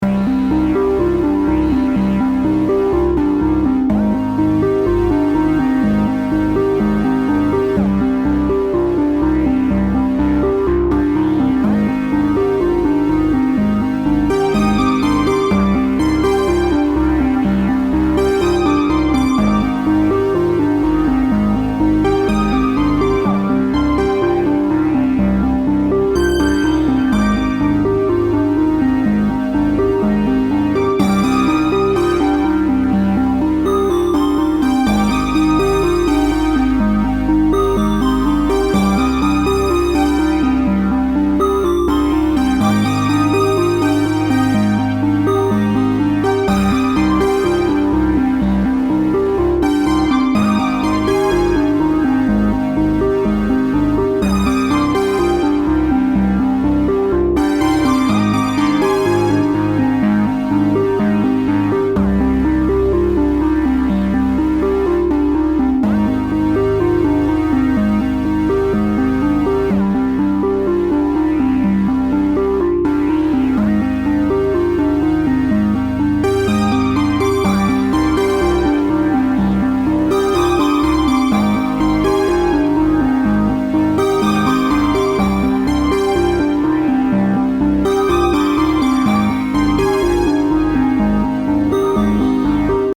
Mesmerizing_Galaxy_Loop.ogg